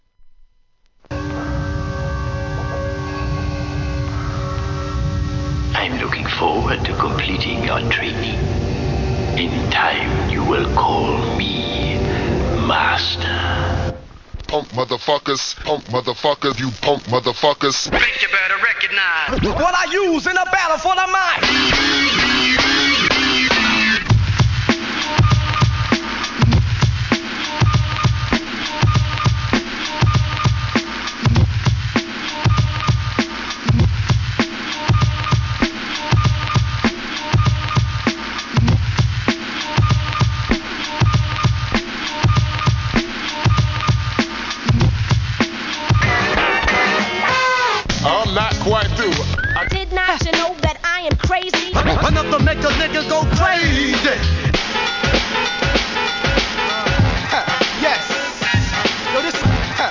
HIP HOP/R&B
ブレイクビーツ、DJ Battle Tool